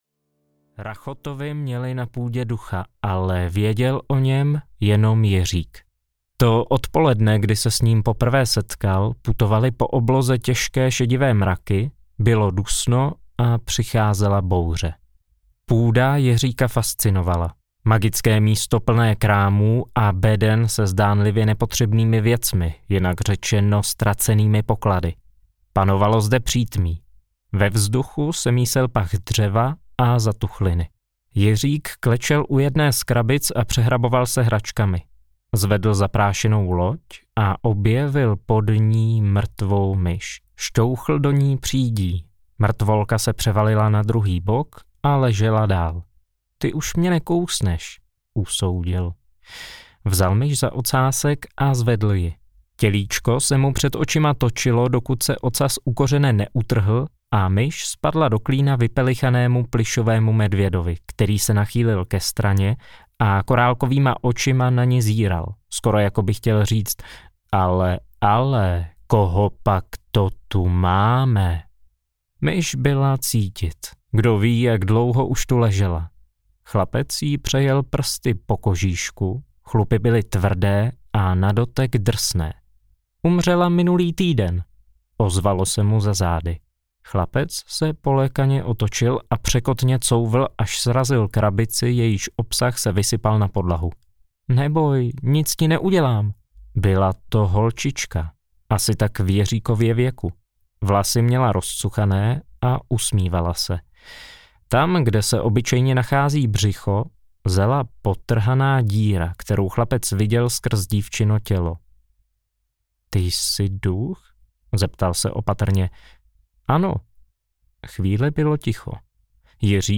Rytíř audiokniha
Ukázka z knihy